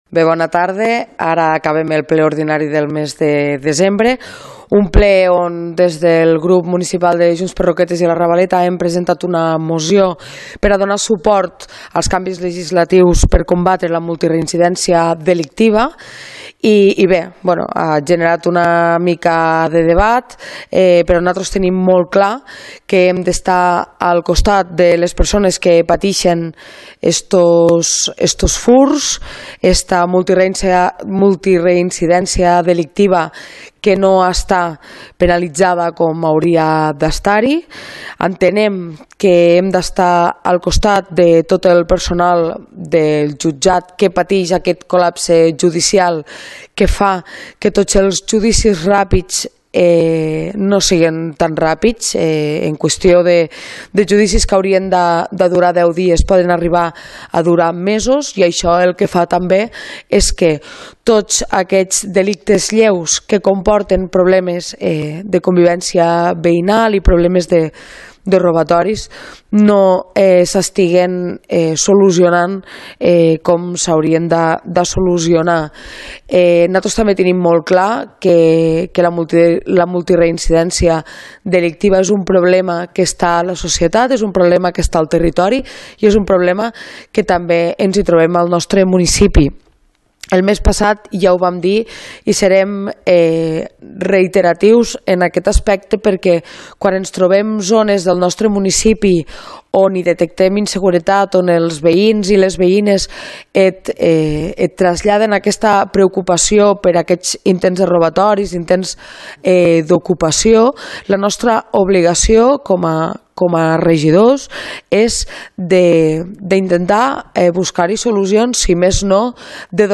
Ple Ordinari de Roquetes – Desembre 2024 – Declaracions – Junts – Lídia Saura | Antena Caro - Roquetes comunicació